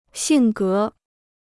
性格 (xìng gé) Free Chinese Dictionary